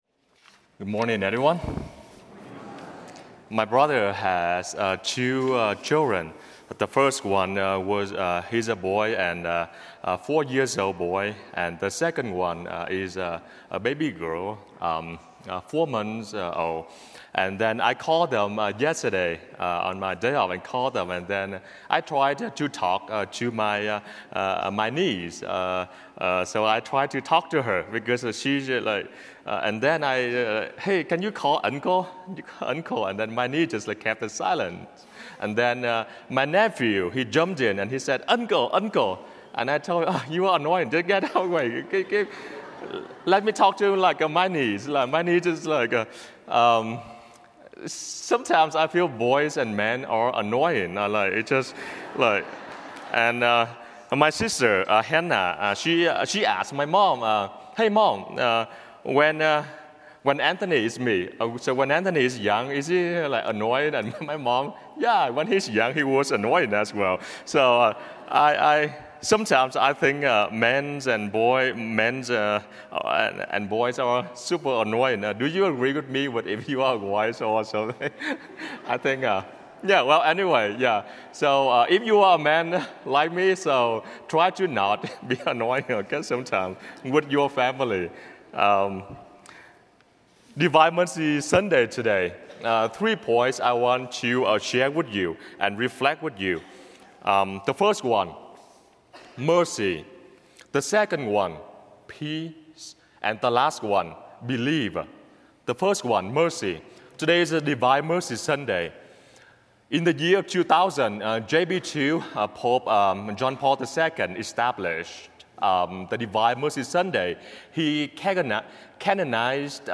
Divine Mercy Sunday Homily